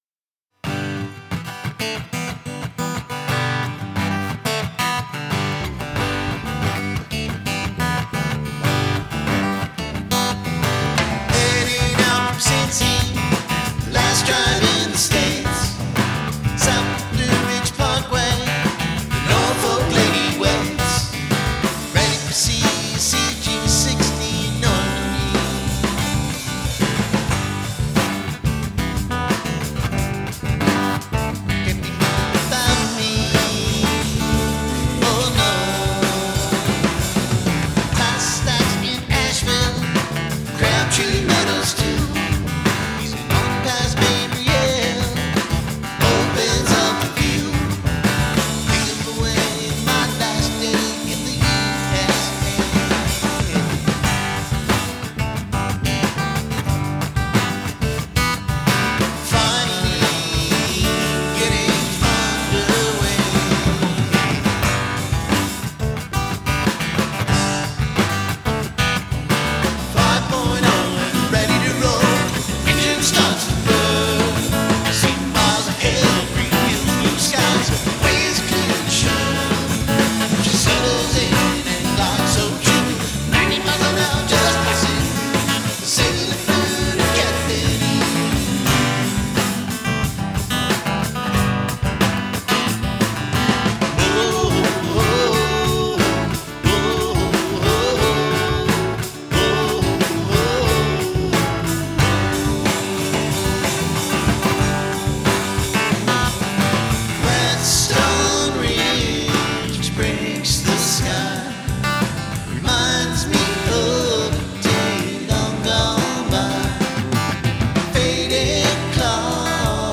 Tempo 90 (of course .